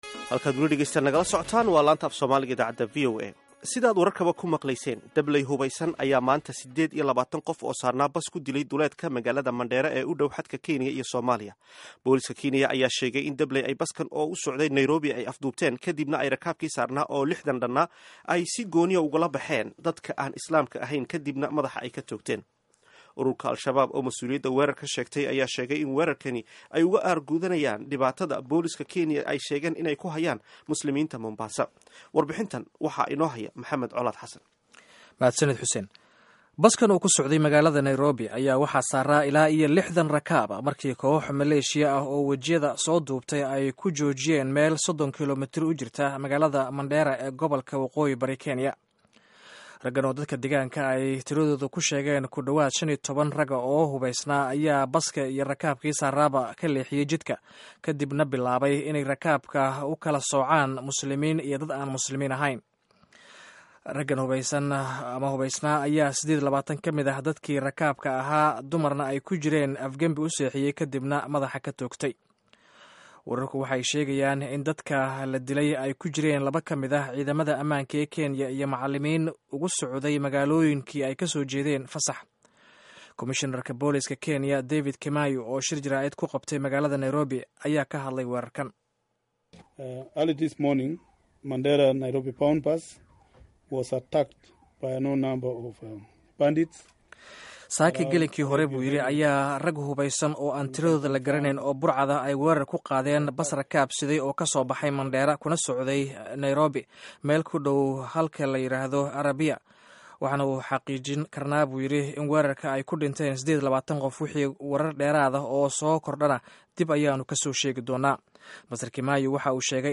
Dhageyso warbixin iyo wareysi ku saabsan weerarka